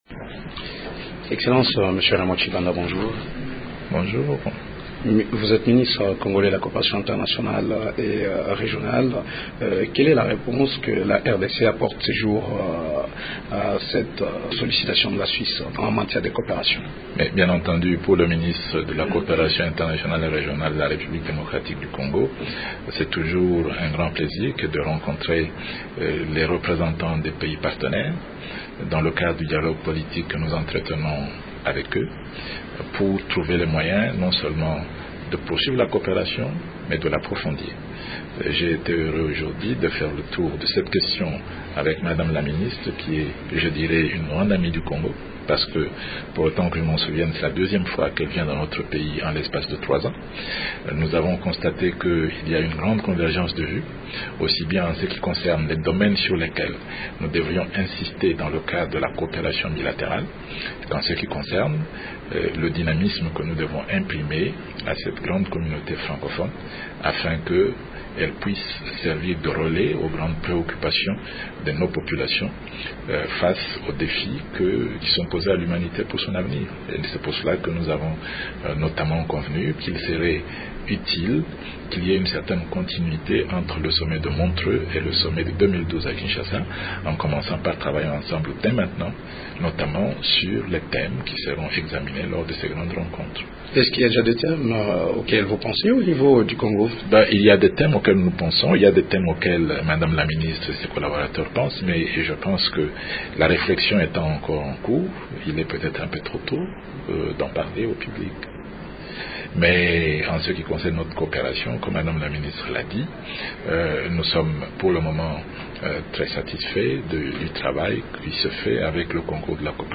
La RDC va succéder à la Suisse à la prochaine présidence de la francophonie en 2012. Nous en parlons avec notre invité du jour, le ministre Raymond Tshibanda.